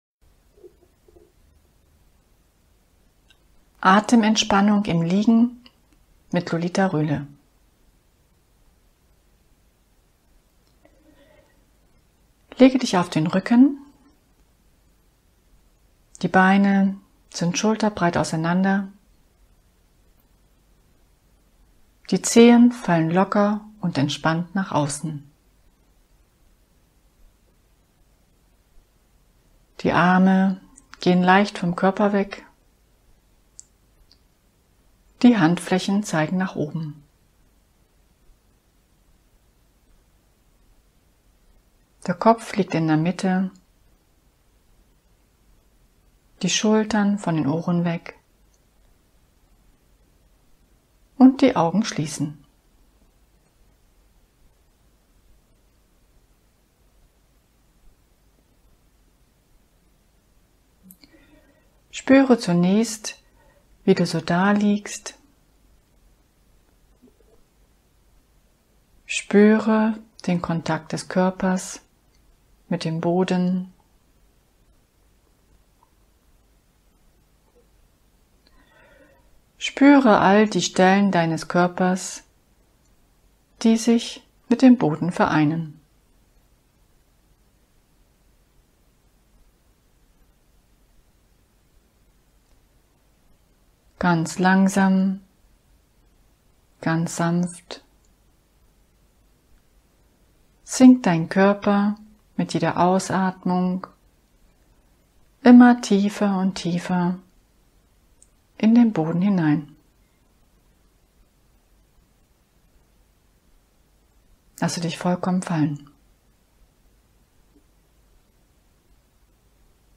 Atem-Entspannung im Liegen / Downloaden | Komm in deine Kraft